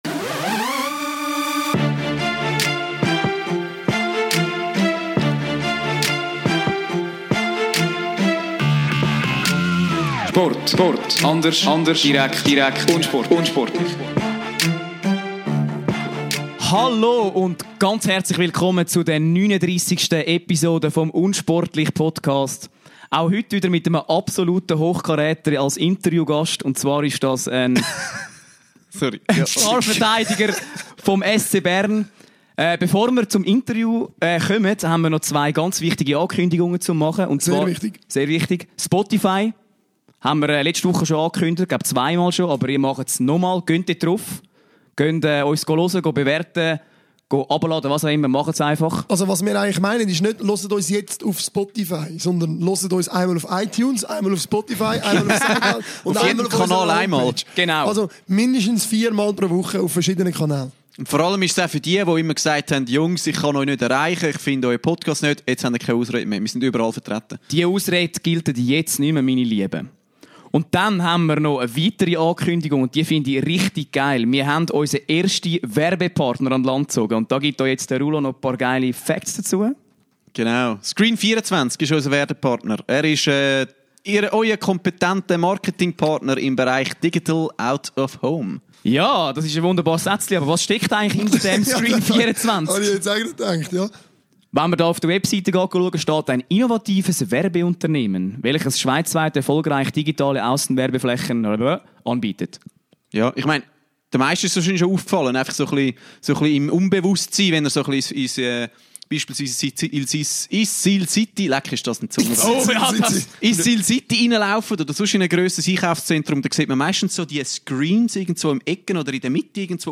03.10.2018 – Mit Ramon Untersander präsentieren wir euch den besten Eishockey-Verteidiger auf heimischen Eis als Interview-Gast. Der gebürtige Rheintaler und SCB- Goalgetter offenbart bei unsportlich seine intime Beziehung mit Trash-Talk-König Tristan Scherwey und wie sie sich gegenseitig zu Höchstleistungen „streicheln“. Zudem lüftet er das Kondom-Geheimnis der vergangenen Winterolympiade in Pyeongchang und verrät uns sein Pre-Game Meal: Bier und Snus!